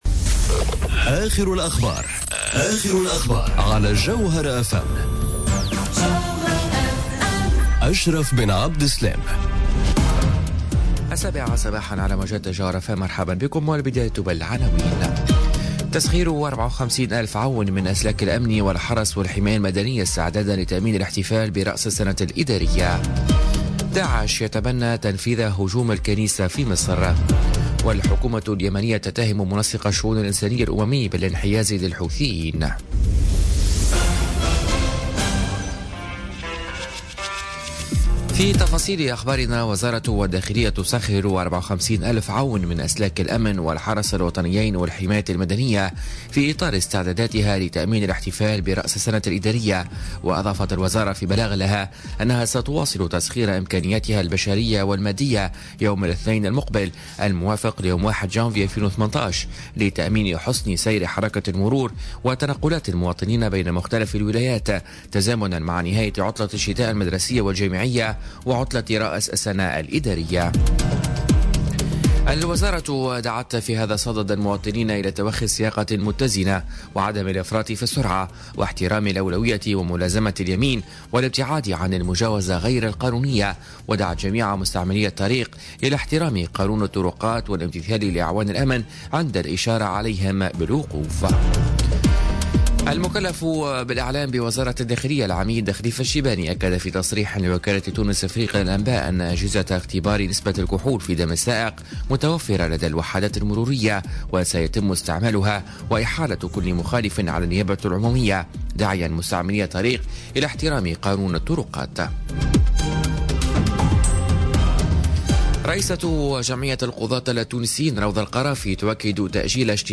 نشرة أخبار السابعة صباحا ليوم السبت 30 ديسمبر 2017